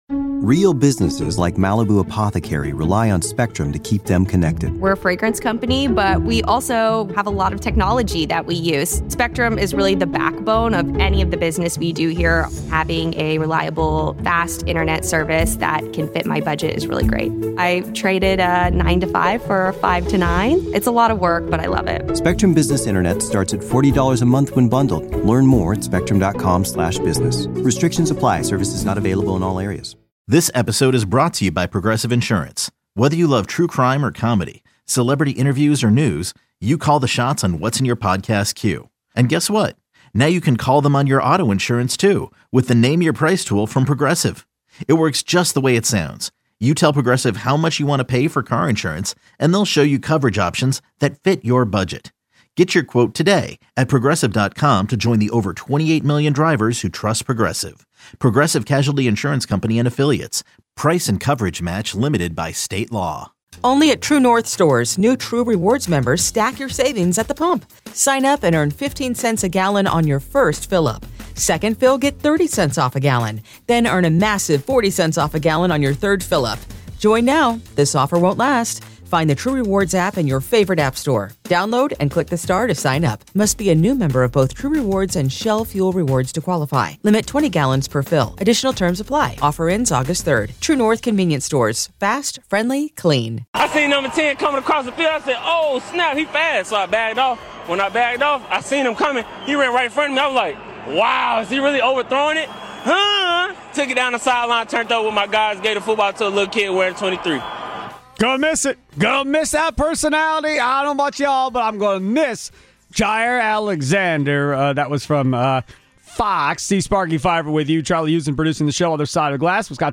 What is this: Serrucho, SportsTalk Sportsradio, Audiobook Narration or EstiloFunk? SportsTalk Sportsradio